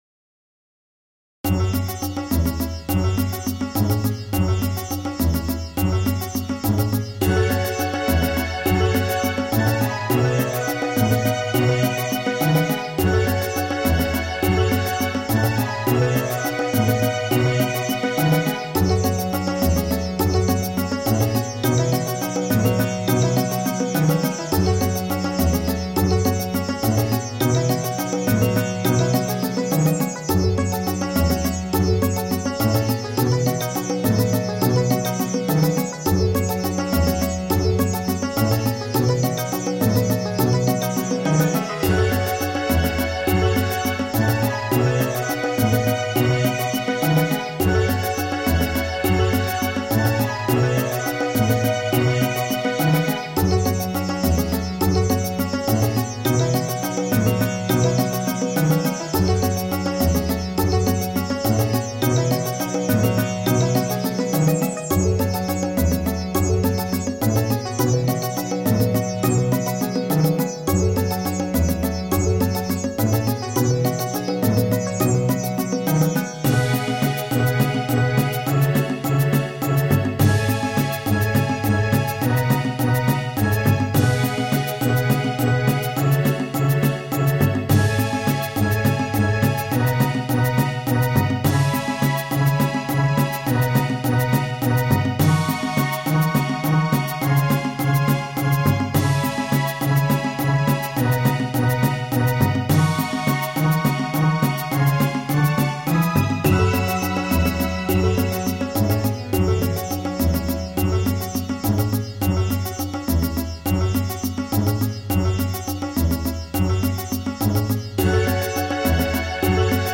打ち込んだ後で気付いたが、これ本当は変拍子にする予定はなかったらしい。